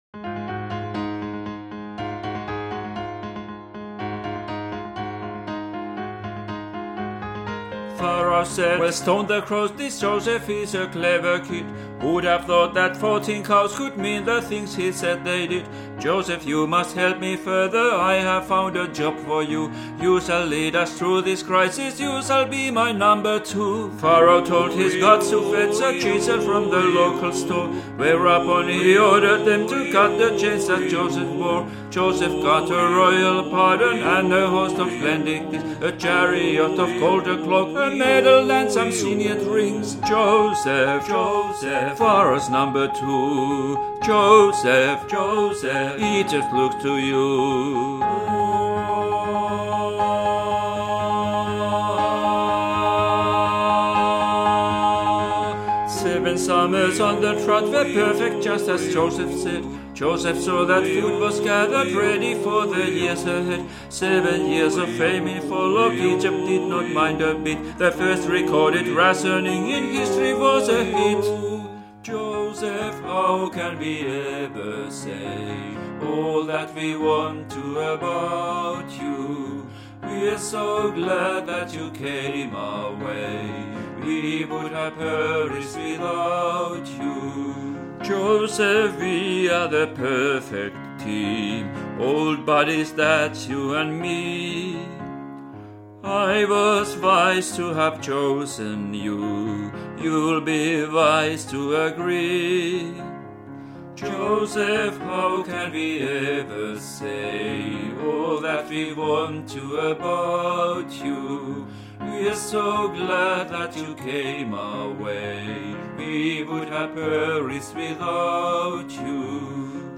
Stone the Crows -Des-duuri - 23.10.2020 13.38.mp3